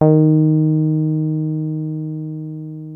303 D#3 5.wav